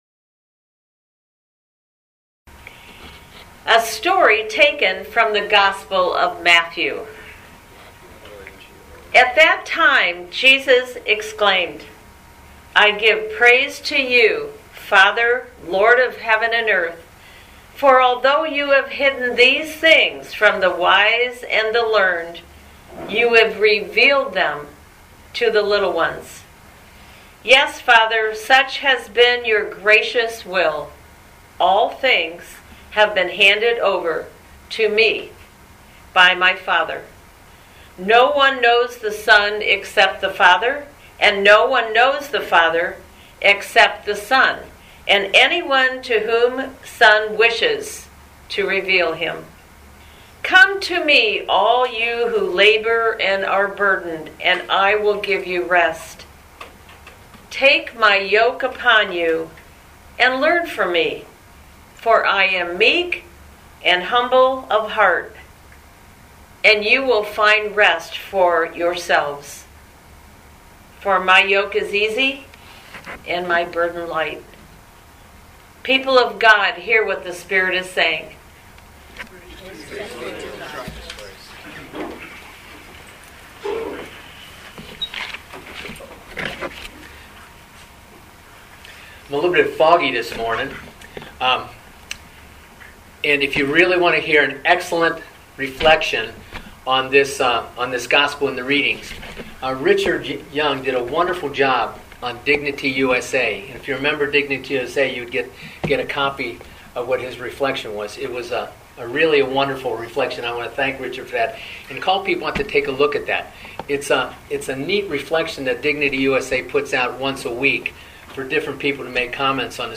Living Beatitudes Community Homilies: My Burden Is Light